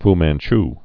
(f măn-ch)